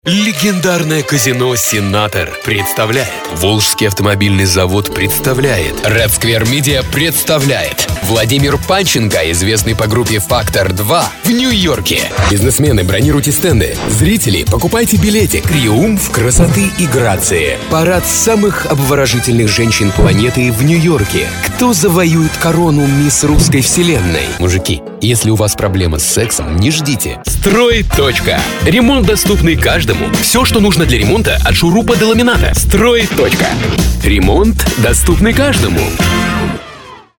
Kein Dialekt
Sprechprobe: Werbung (Muttersprache):
Studio Equipment: 4×6 Double-wall booth by “Whisper Room” Microphones: Rode NT2000 Condencer Mic. AT5040 Condencer Mic. Sennhieser 416 Condencer Mic. Sennhieser 441U Dynamic Mic. Shure SM7B Dynamic Mic. Other stuff: Presonus “Studio Live” mixer board (Firewire) MacPro Computer Source Connect, Phone/Skype patch. FiOS Internet Connection
commercial.mp3